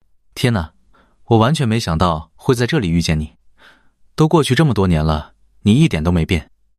Neutral1.mp3